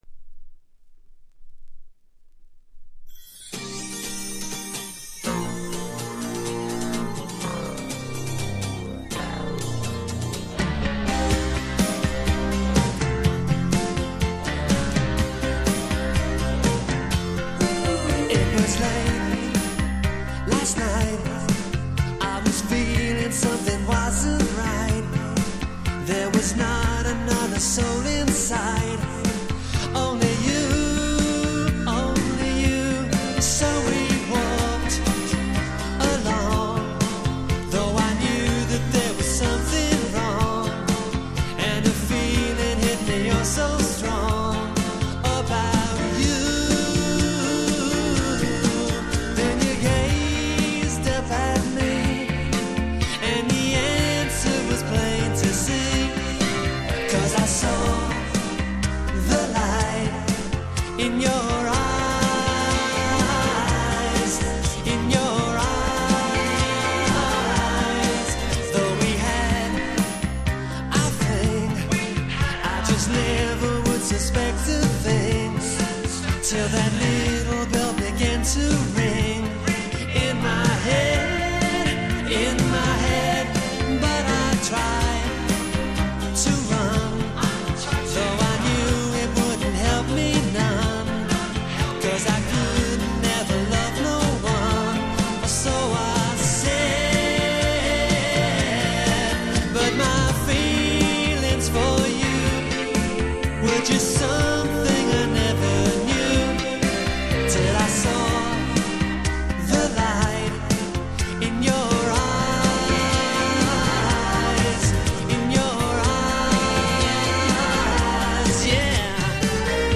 ストリングスが美しい
映画音楽チェイスシーンで使われそうな